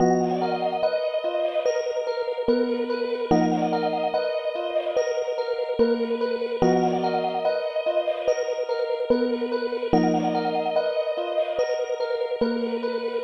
蔑视这个 桥垫 145bpm
描述：陷阱和科幻的结合。沉重的打击和神秘感。使用这些循环来制作一个超出这个世界范围的爆炸性节目吧 :)D小调
标签： 145 bpm Trap Loops Pad Loops 2.23 MB wav Key : D
声道立体声